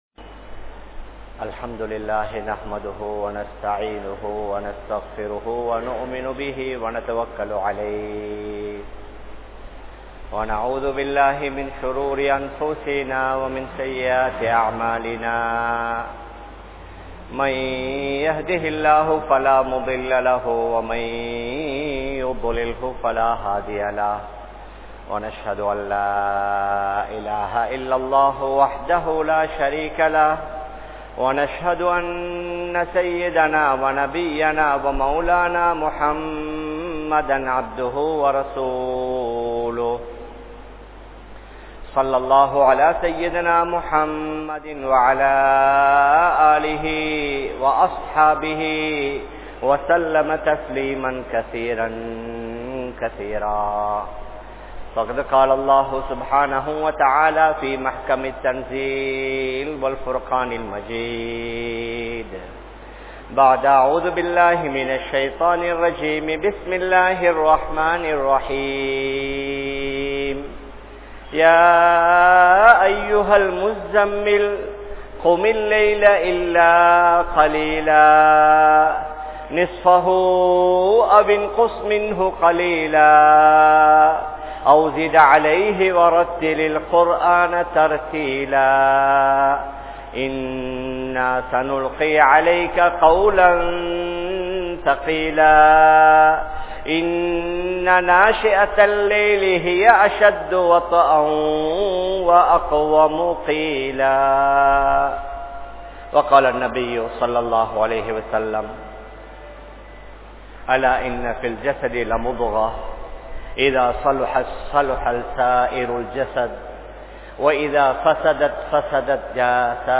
Noanpu Kaalaththil Pirachchinai Padaatheerhal (நோன்பு காலத்தில் பிரச்சினை படாதீர்கள்) | Audio Bayans | All Ceylon Muslim Youth Community | Addalaichenai